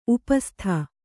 ♪ upastha